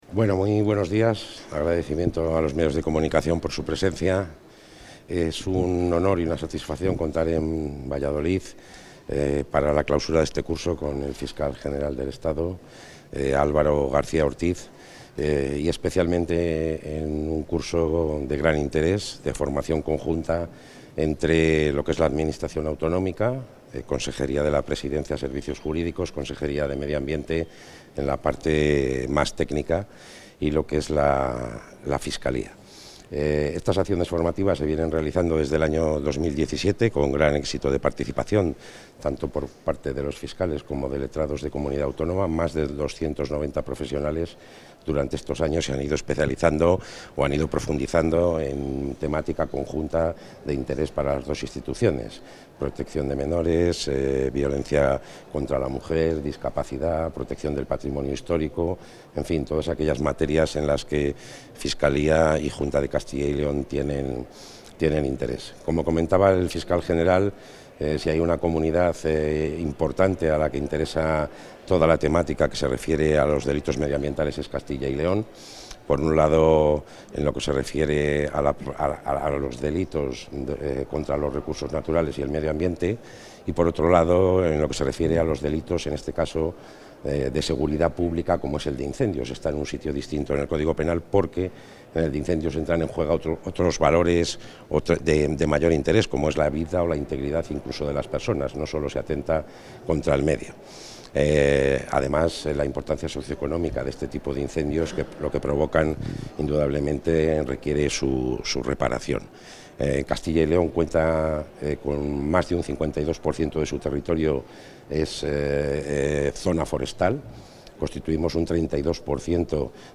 Intervención del consejero.
Así se ha puesto de manifiesto en el curso ‘Delitos contra el Medio Ambiente. Incendios Forestales’, organizado conjuntamente por los Servicios Jurídicos autonómicos y la Fiscalía, y que ha sido clausurado por el Consejero de la Presidencia, Luis Miguel González Gago, y el Fiscal General del Estado, Álvaro García Ortiz.